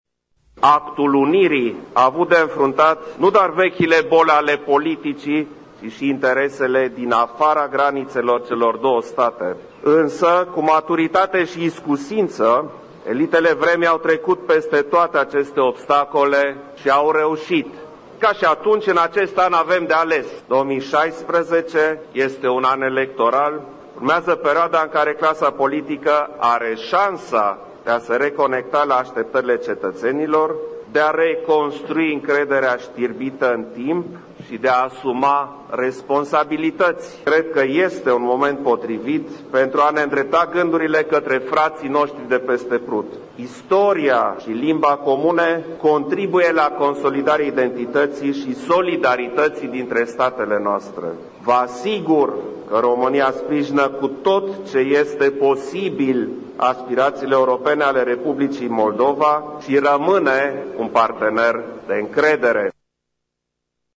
În discursul susţinut azi la orele prânzului, şeful statului a făcut un apel la unitate, dar şi la reformarea şi modernizarea societăţii româneşti, folosind prilejul pentru a da exemplul înaintaşilor care au făcut posibil momentul de la 24 ianuarie 1859: